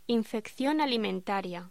Locución: Infección alimentaria